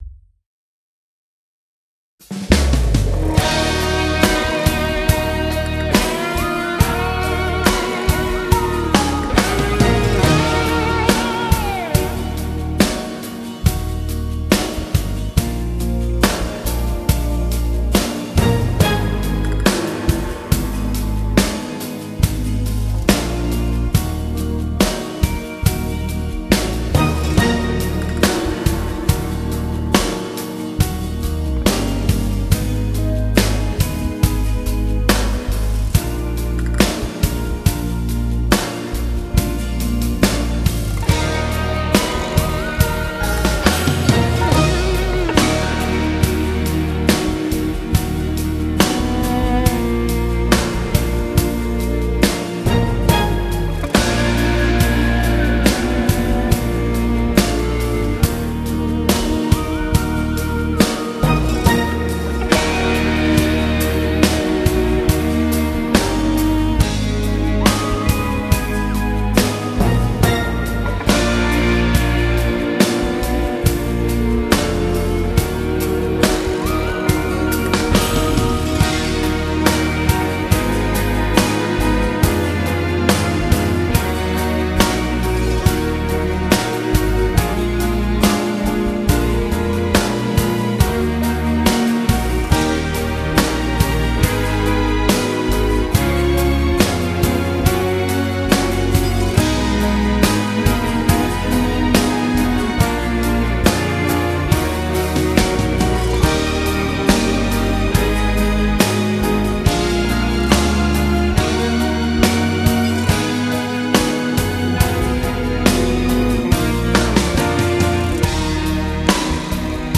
Genere: Lento
Scarica la Base Mp3 (3,94 MB)